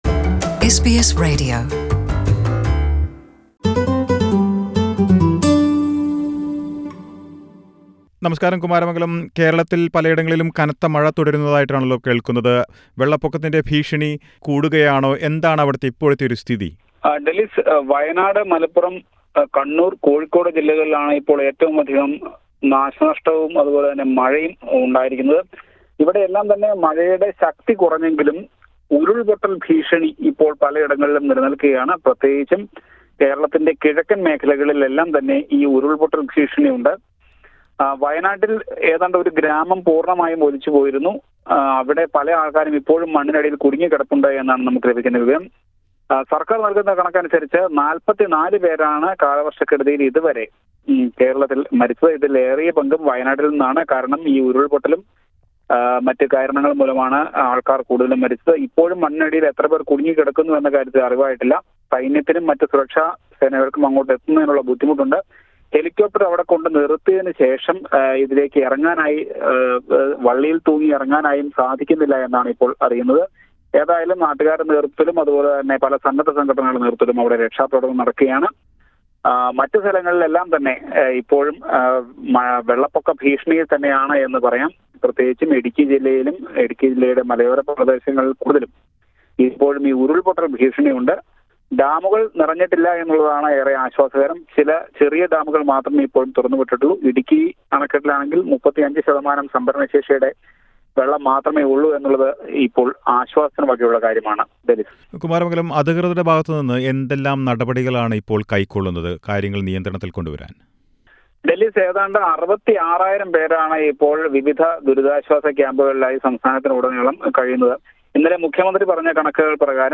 Authorities say more than 40 people have died in Kerala and the toll could rise with more people missing. Weather experts expect rainfall to reduce in the coming days. Listen to the report from Kerala.